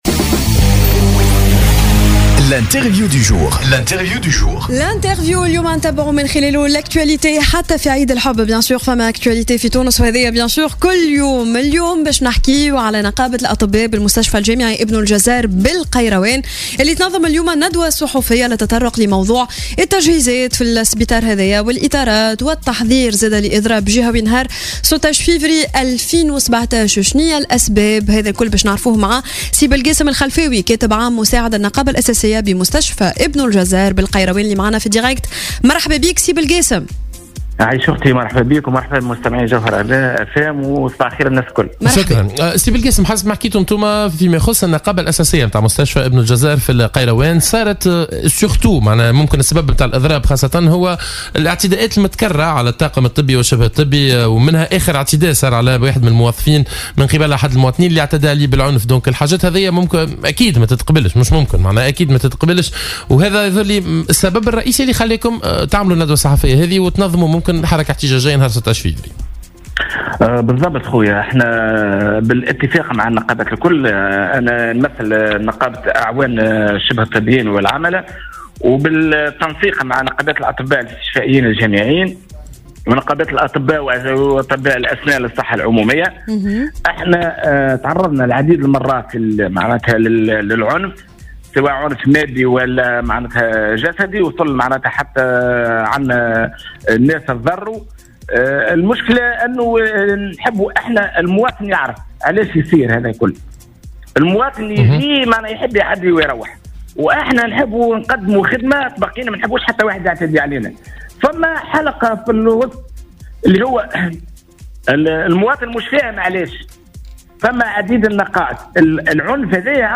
Dans son intervention ce matin sur les ondes de Jawhara FM